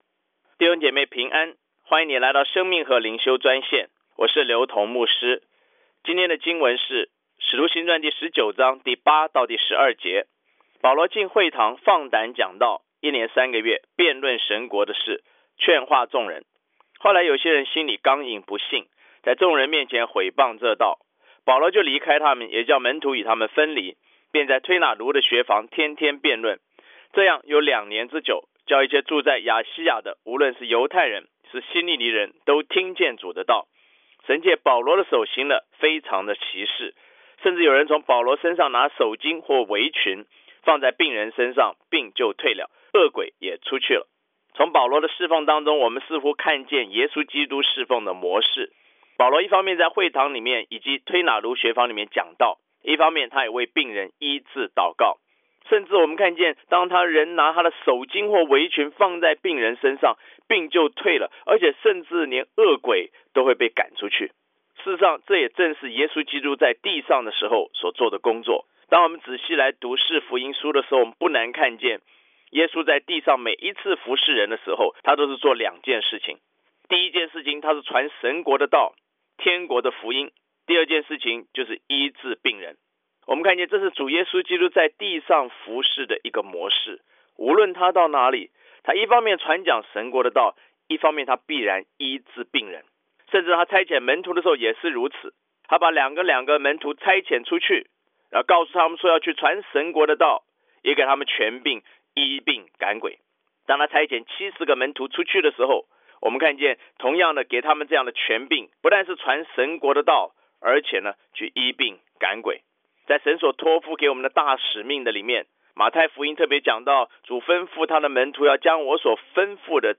藉着每天五分钟电话分享，以生活化的口吻带领信徒逐章逐节读经。